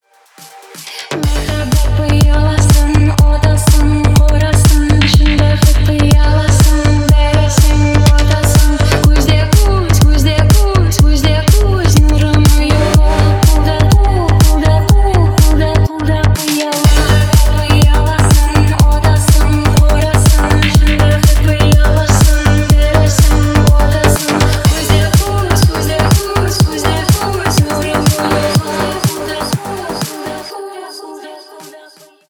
• Качество: 320, Stereo
progressive house